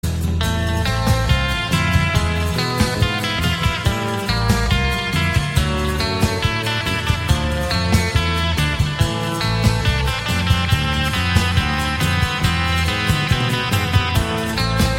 • Качество: 128, Stereo
без слов
инструментальные
рок